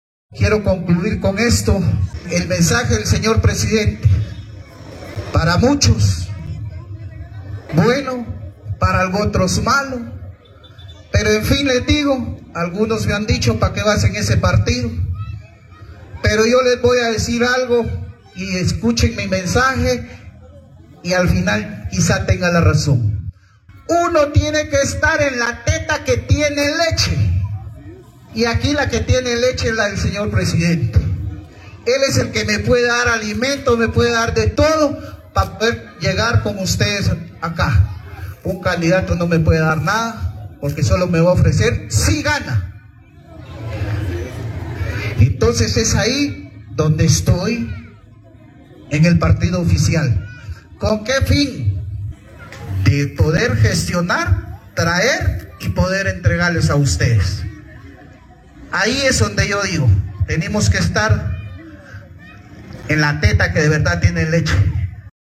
Declaraciones del alcalde de Cuilapa, Esvin Fernando Marroquín Túpas, cuando anunció que se sumaba a Vamos en enero 2023.